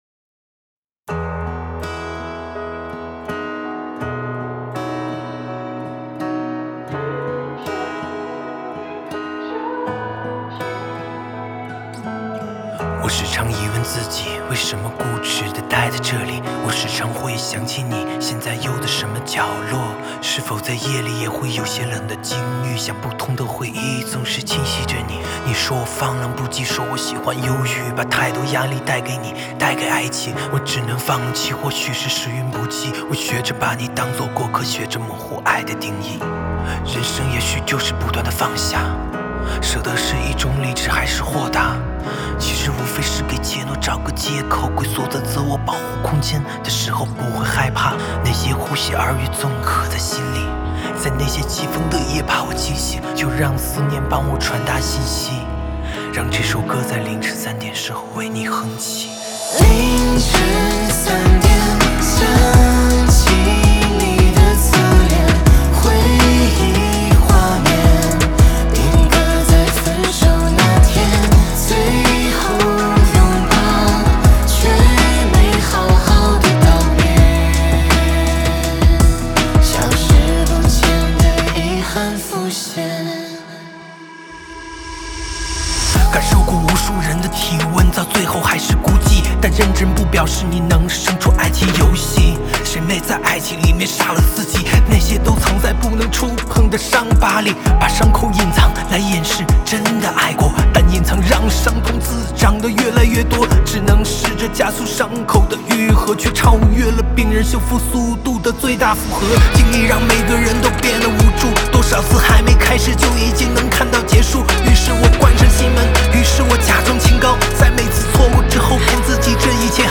Ps：在线试听为压缩音质节选，体验无损音质请下载完整版
吉他
伴唱